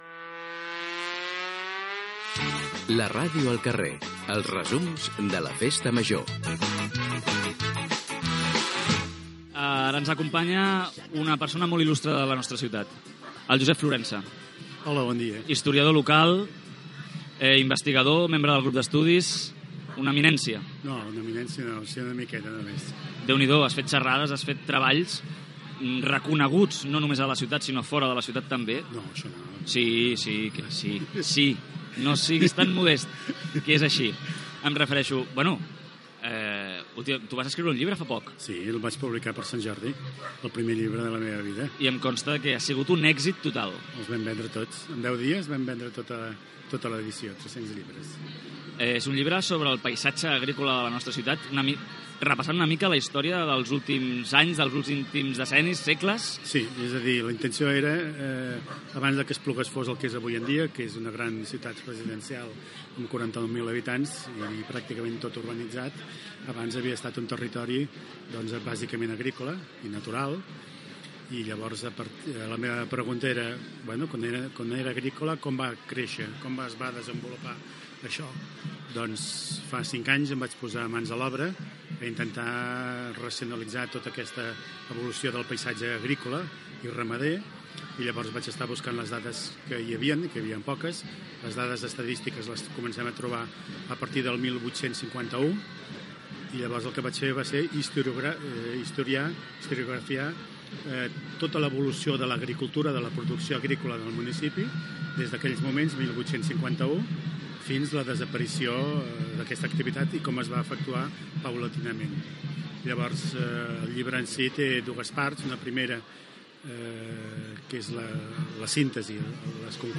Entreteniment